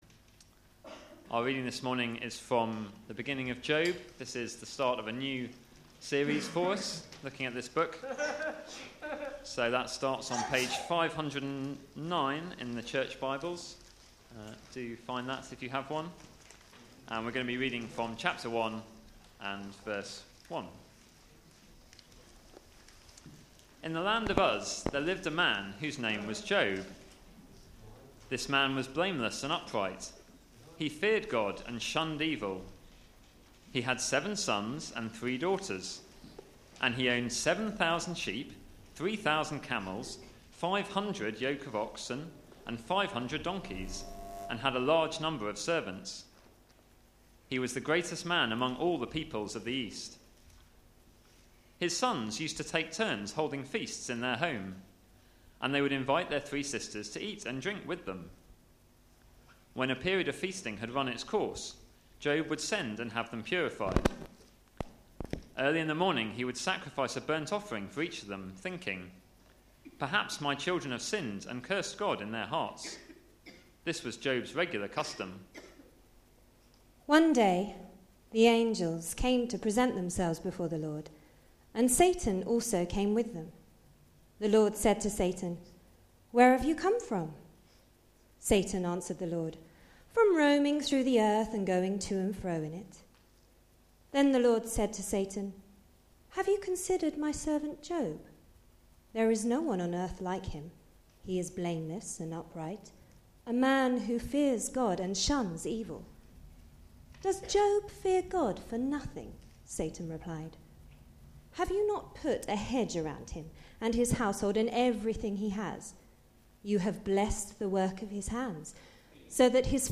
Media for Sunday Service on Sun 07th Apr 2013 11:00